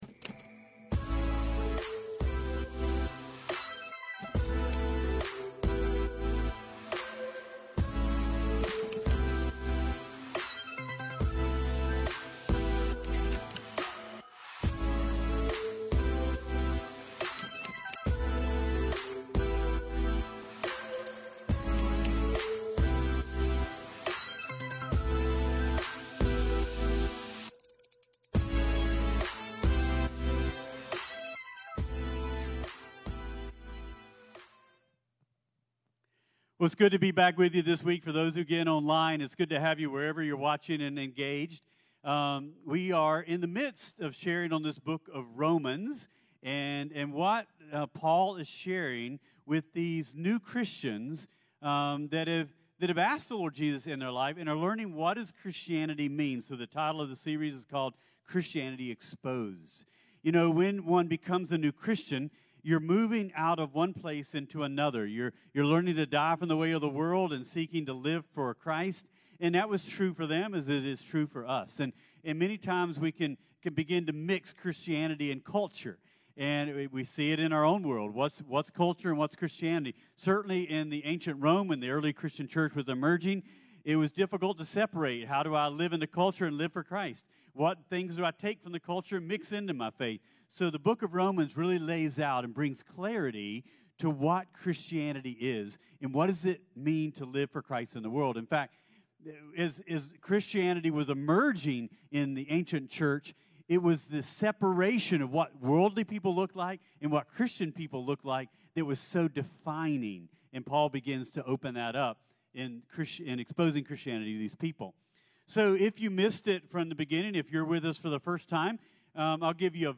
CTK-Sermon-3-1.mp3